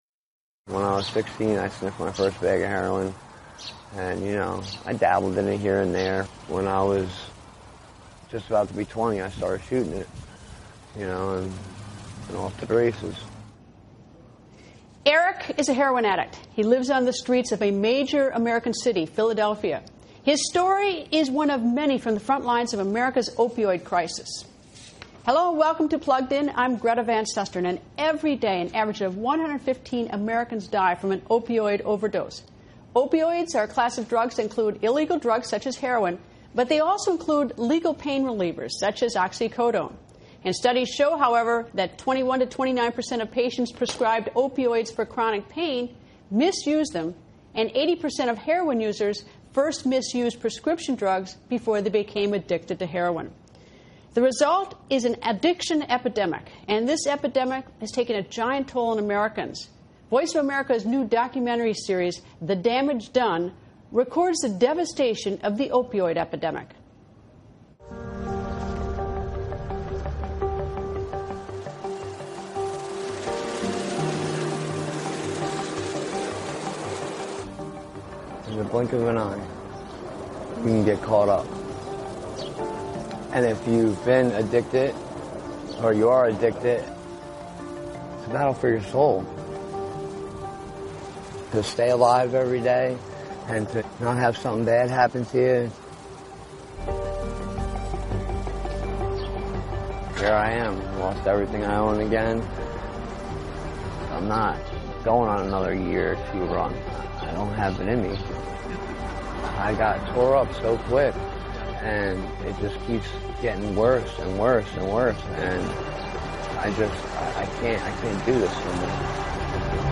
Why is there an opioid crisis in America and how do we fight it? Florida Attorney General Pam Bondi join's Greta Van Susteren from Tampa and in the studio, a man who struggled with opioid addiction talks about his experience.